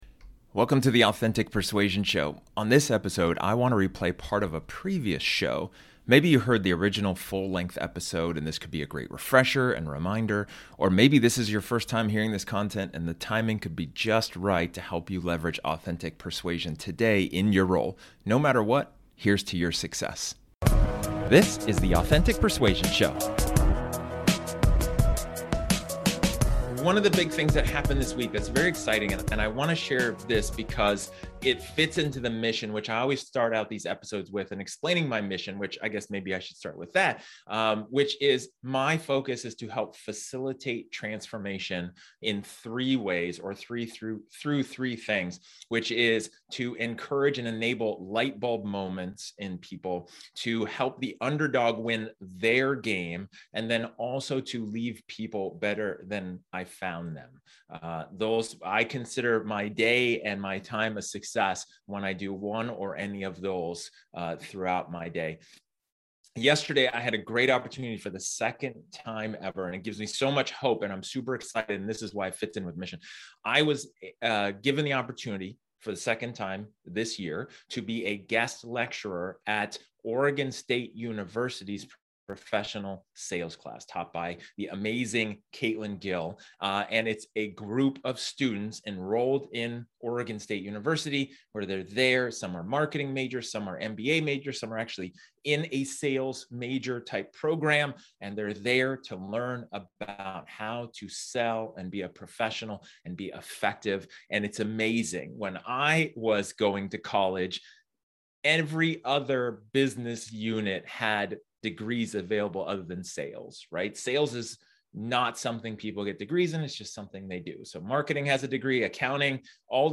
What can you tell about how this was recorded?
This episode is an excerpt from one of my training sessions where I talk about effective empathy.